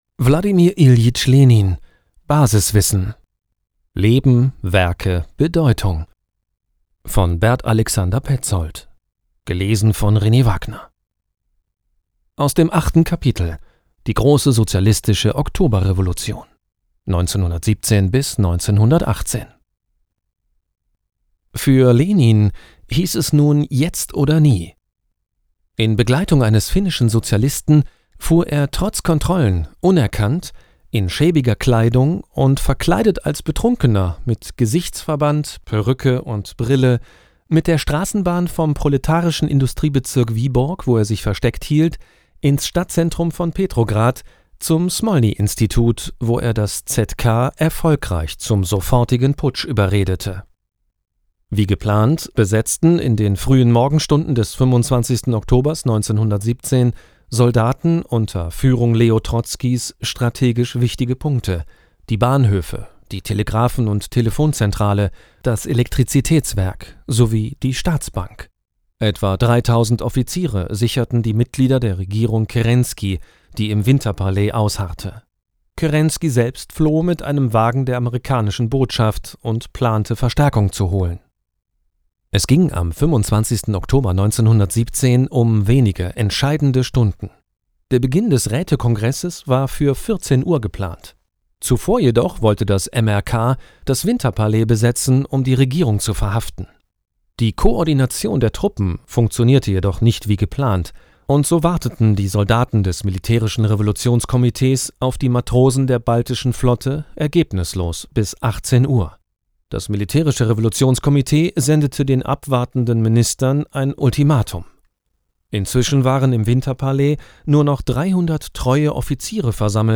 Hörbuch: Wladimir Iljitsch Lenin (2 CDs) – Basiswissen